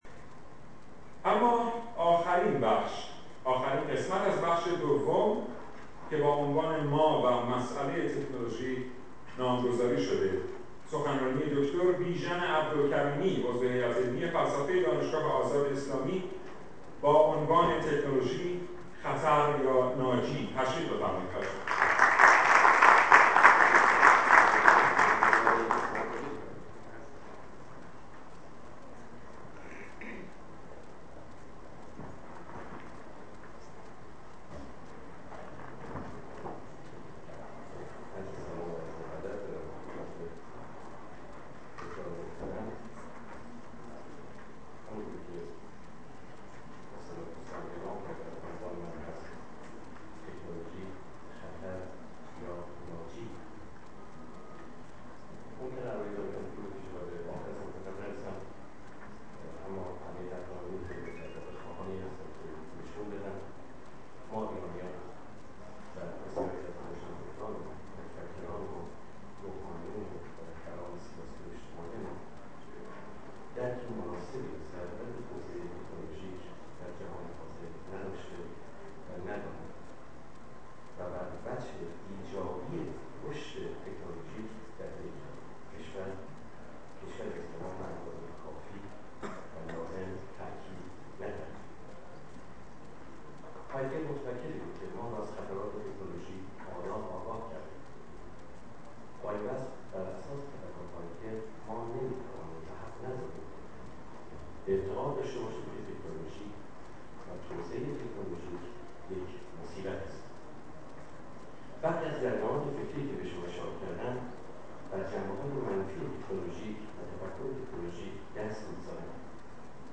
سخنرانی
در اولین همایش ملی فرهنگ و تکنولوژی